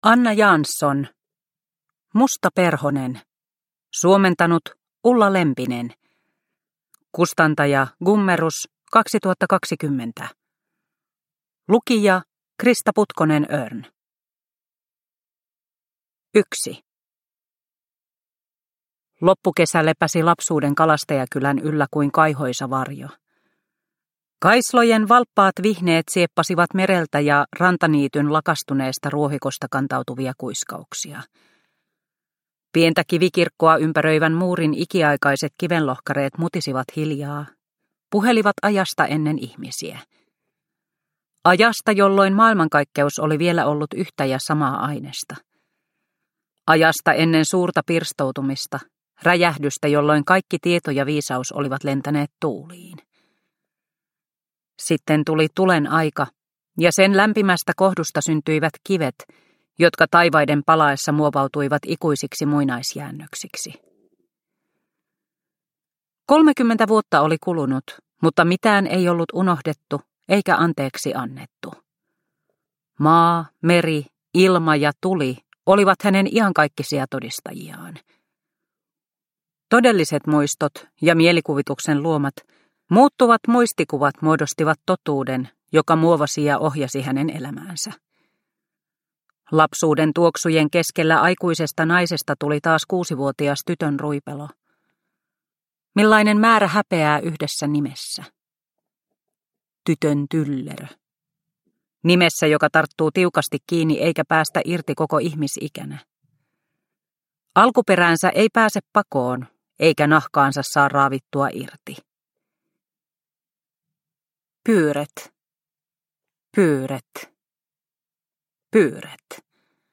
Musta perhonen – Ljudbok – Laddas ner